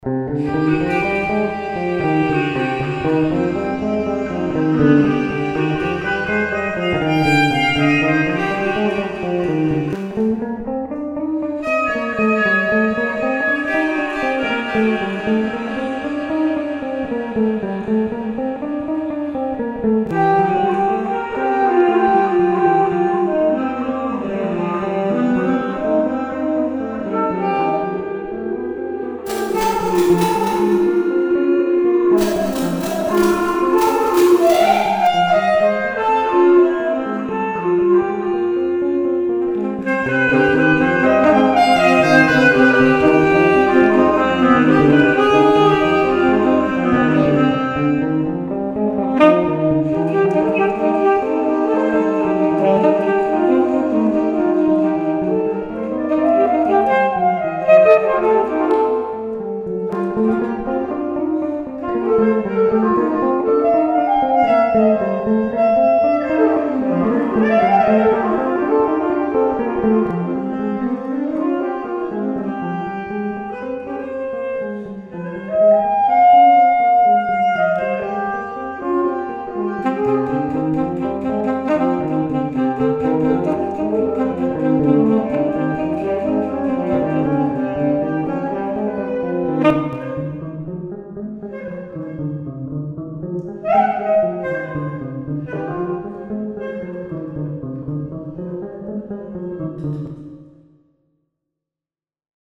MusiChess-hymn.mp3